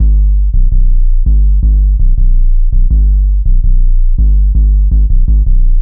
Index of /90_sSampleCDs/Zero-G - Total Drum Bass/Instruments - 1/track06 (Bassloops)